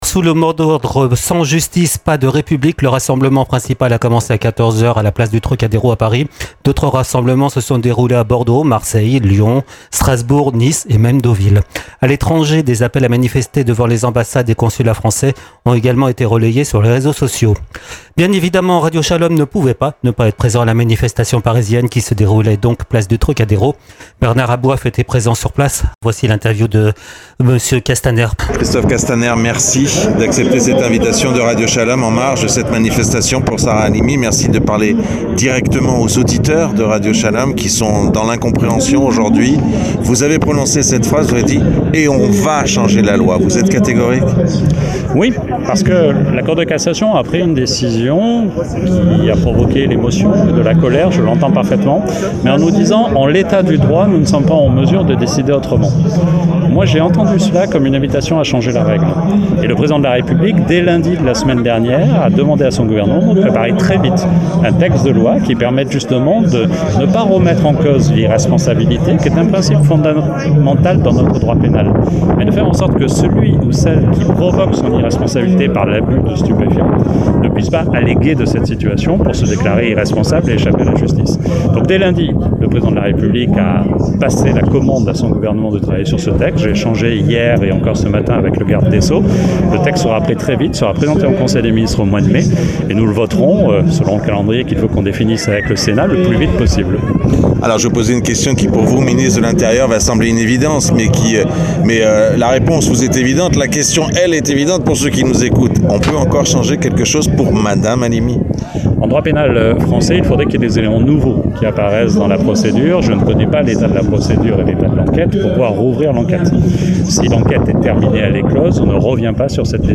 Place du Torcadéro, à Paris.